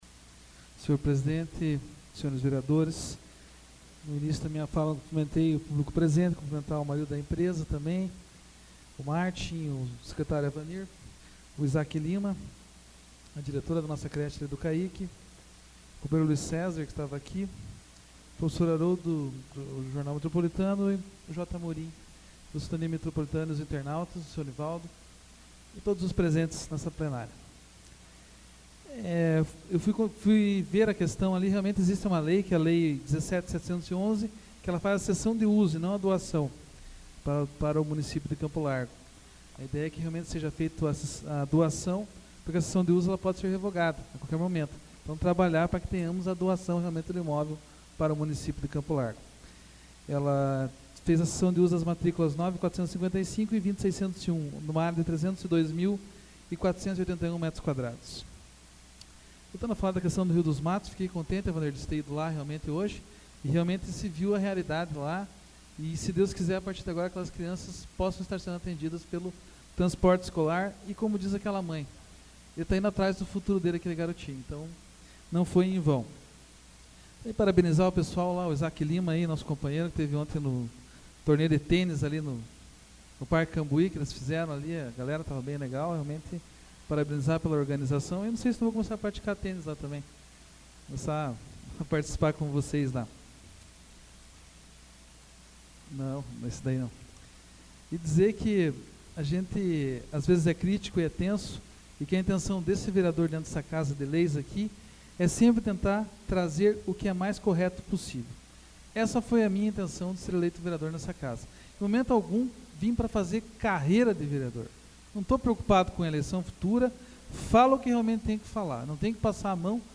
Explicação pessoal AVULSO 25/02/2014 João Marcos Cuba